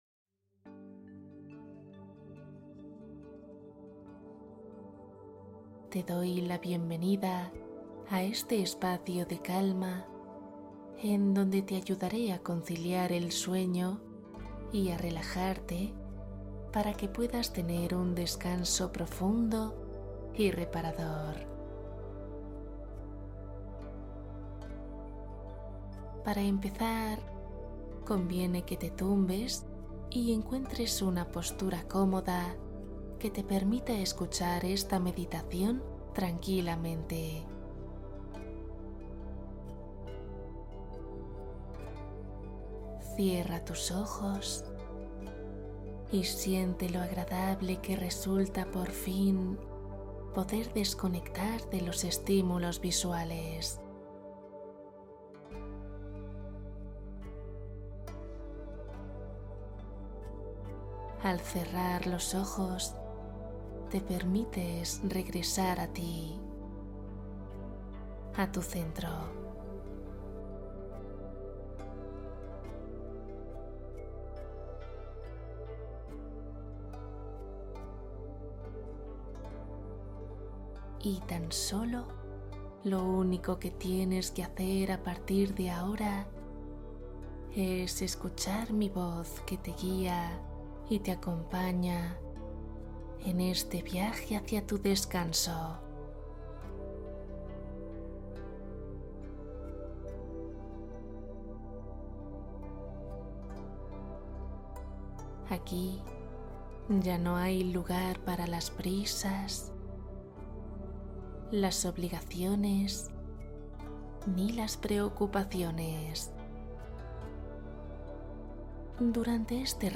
Cuento + Relajación Meditación guiada para dormir profundamente